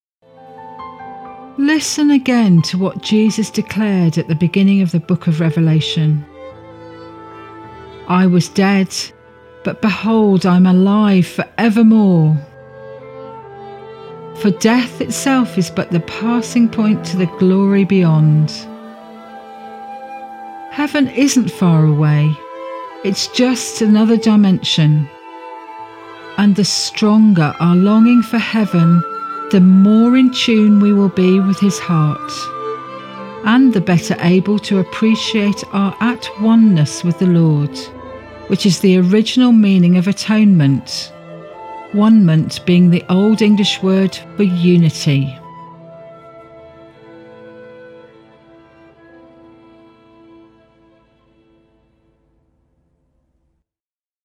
Improvisation